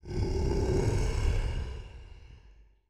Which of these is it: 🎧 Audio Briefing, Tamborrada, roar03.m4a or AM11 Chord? roar03.m4a